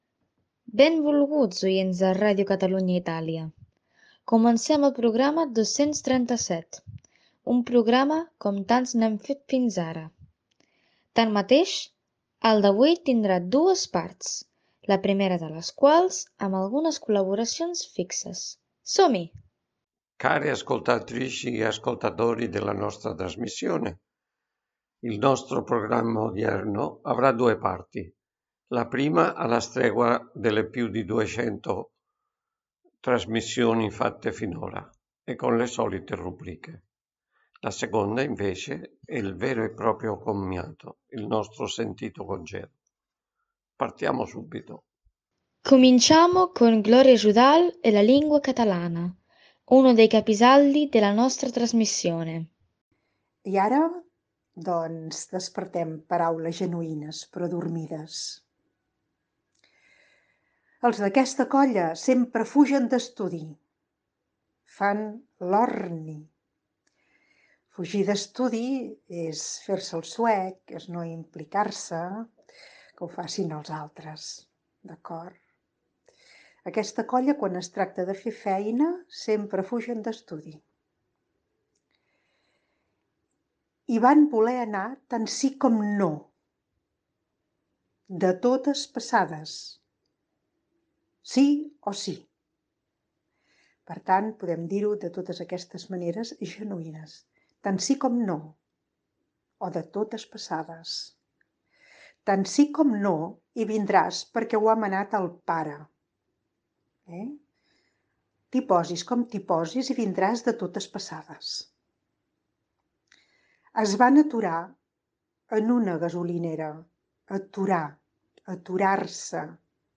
Últim programa de la ràdio.
Cultural